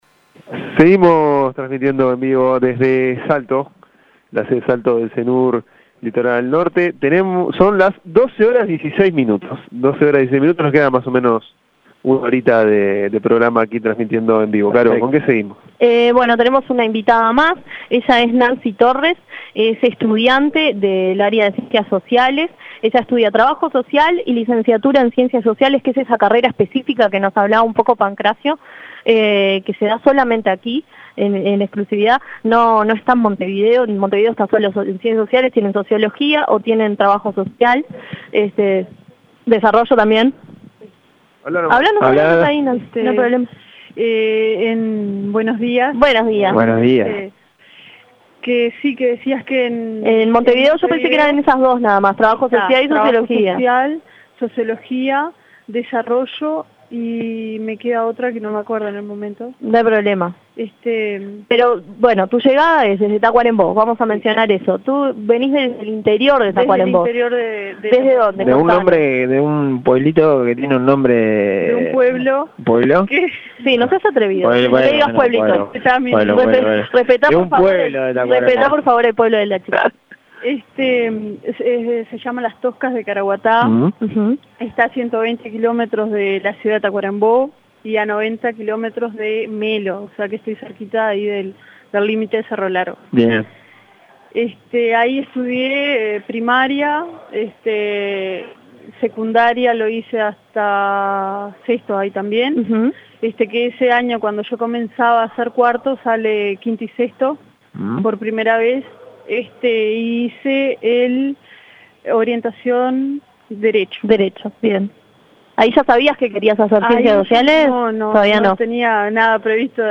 Audio: Uni Radio en vivo desde la Sede Salto Regional Norte
La Mañana de UNI Radio viajó hacia el norte, más precísamente a la ciudad de Salto, para visitar y conocer la Sede Salto Regional Norte de la Udelar. El equipo de La Mañana dialogó con el Intendente de Salto, Andrés Lima , quién destacó la importancia de la sede en el desarrollo de Salto como ciudad y sobre el Estado actual del departamento a meses de iniciar su gestión.